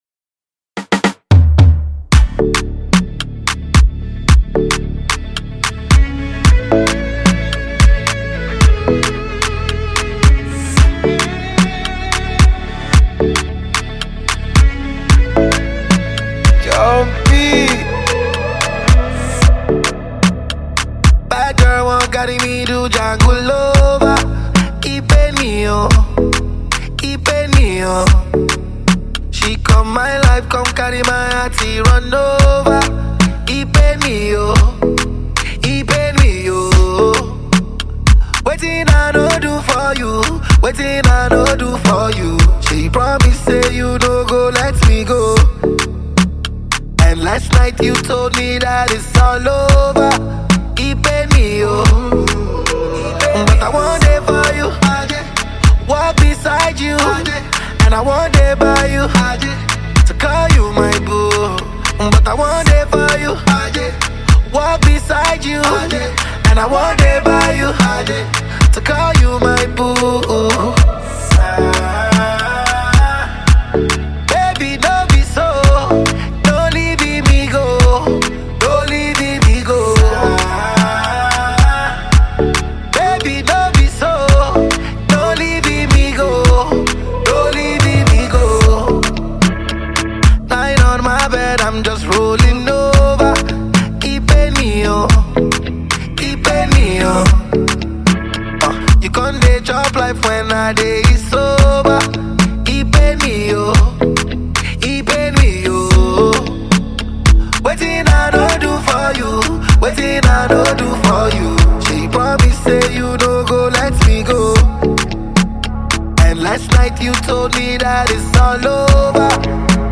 soul-soothing music